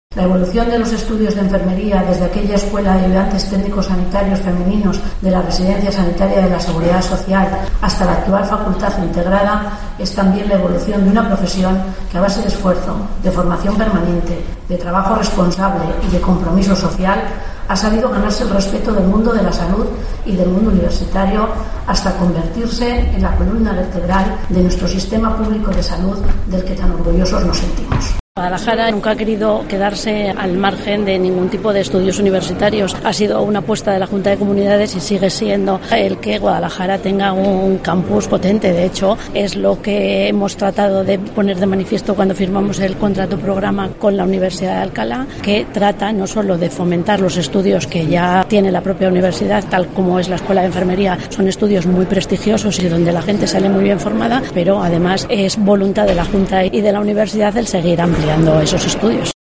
Durante la apertura de los actos organizados con motivo del 50 aniversario de la Escuela de Enfermería de Guadalajara, la viceconsejera de Educación, Universidades e Investigación, María Dolores López, ha destacado el prestigio y el reconocimiento con que cuenta actualmente el grado de enfermería impartido en Guadalajara.